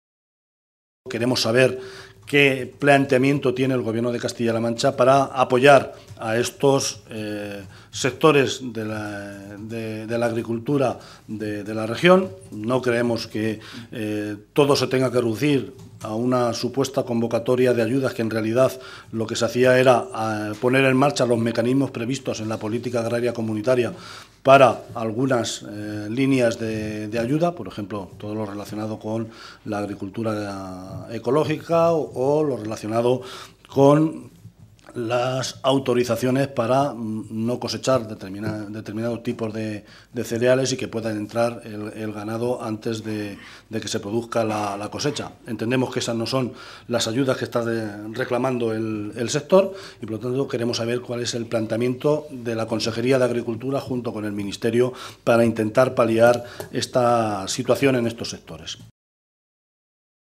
José Luis Martínez Guijarro, en rueda de prensa
Cortes de audio de la rueda de prensa